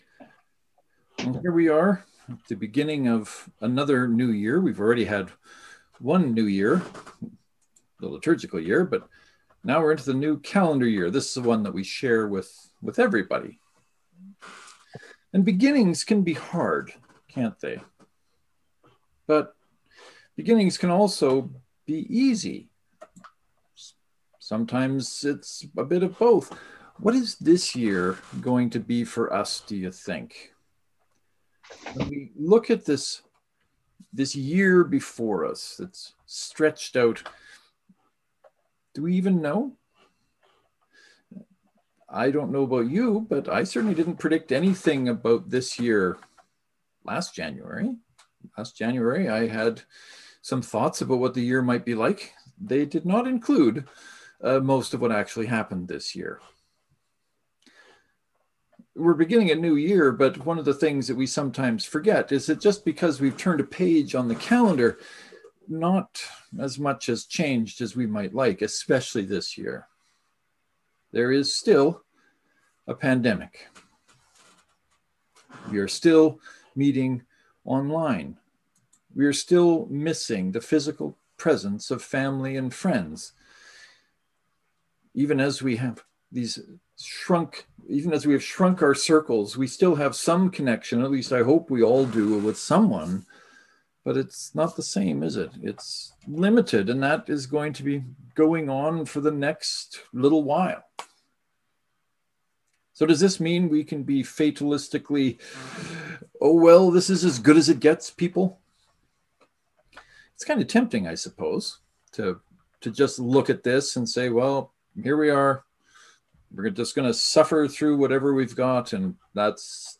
This Sunday’s sermon is about hope and it even begins in Jeremiah . . . go figure.
“Let us begin” Knox and St. Mark’s Presbyterian joint service (to download, right click and select “Save Link As . . .”)